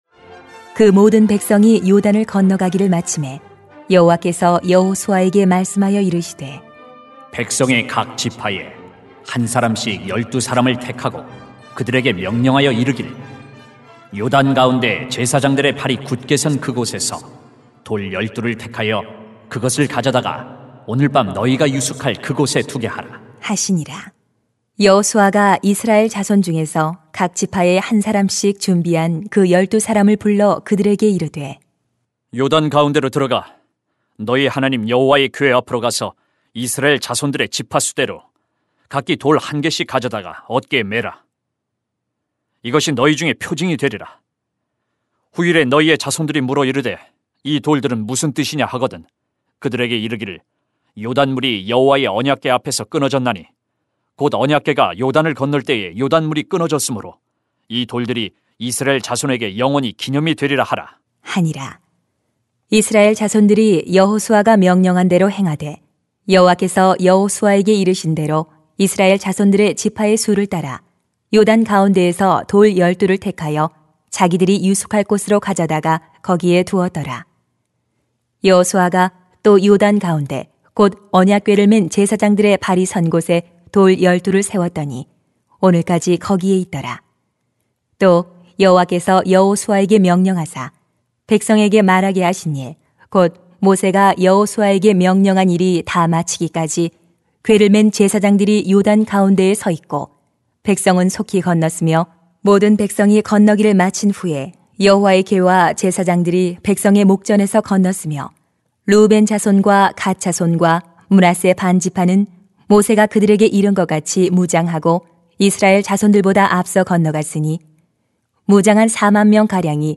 [수 4:1-24] 기념물을 세웁시다 > 주일 예배 | 전주제자교회